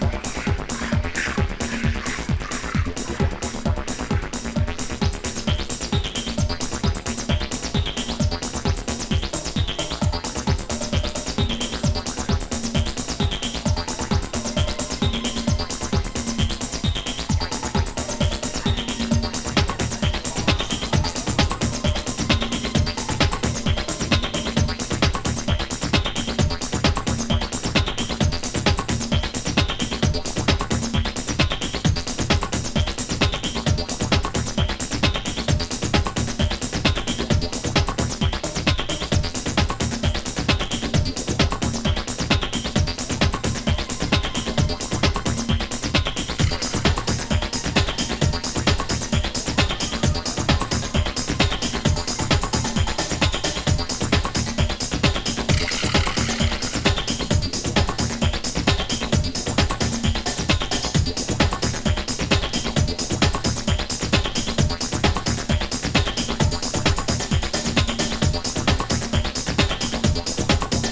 DubもTranceもBreakbeatもMixされた独自のサウンド。